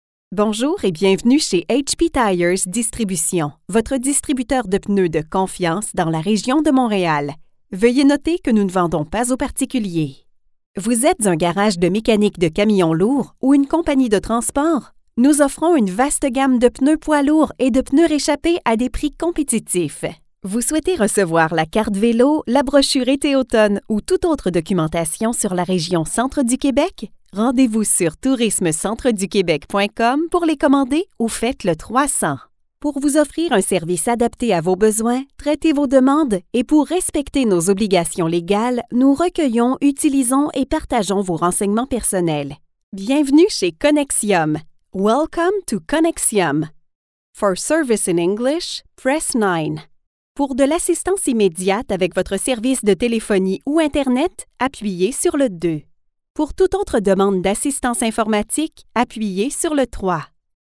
French Canadian IVR Demo
French Canadian, Quebec French, neutral French
Young Adult
Middle Aged